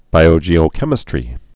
(bīō-jēō-kĕmĭ-strē)